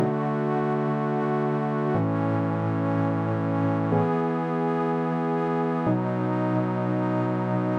模拟导线垫
描述：模拟4由octatrack录制
Tag: 123 bpm Electronic Loops Pad Loops 1.31 MB wav Key : Unknown